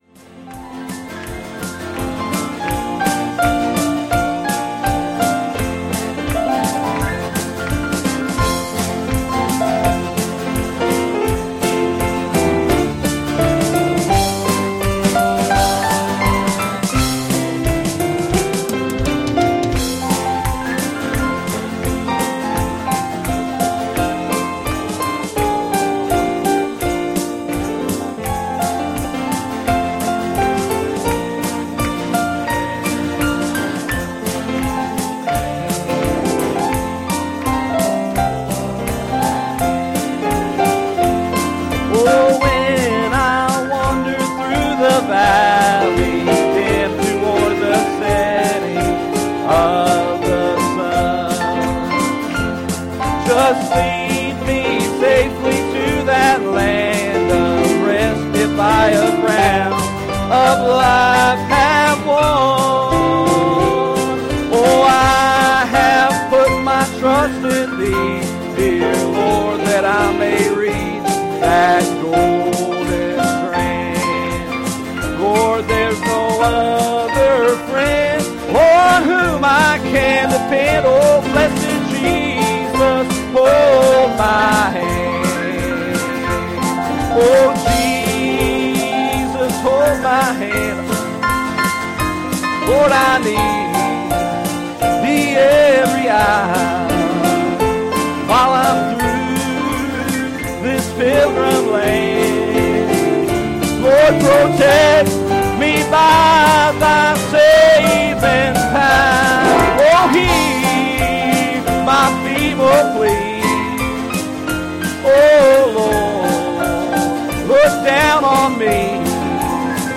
Passage: Revelation 10:8 Service Type: Special Service